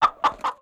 duck_02.wav